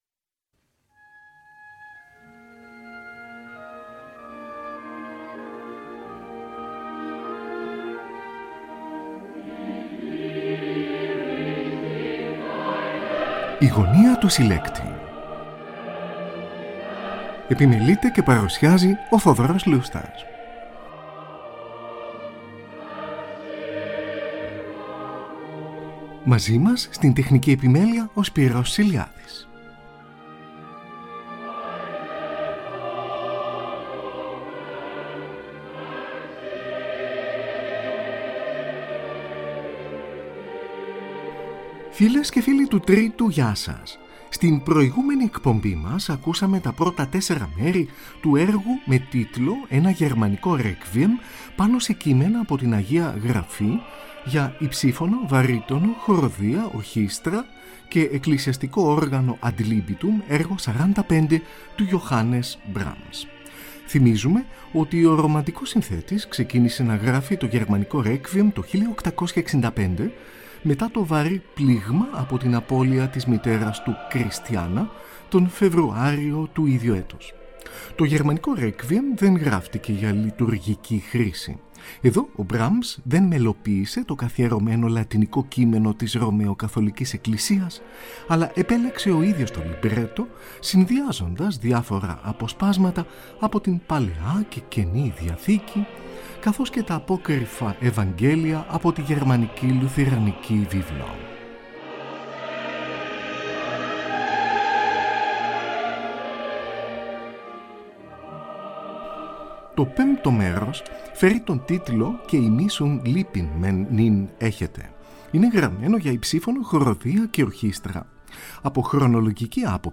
Johannes Brahms: Γερμανικό Requiem, για υψίφωνο, βαρύτονο, χορωδία, ορχήστρα και εκκλησιαστικό όργανο ad libitum, έργο 45: πέμπτο, έκτο & έβδομο μέρος:
Tην Ορχήστρα Konsertförenings και τη Χορωδία Musikaliska Sällskapet της Στοκχόλμης διευθύνει ο Wilhelm Furtwängler, από ζωντανή ηχογράφηση. 19 Νοεμβρίου 1948.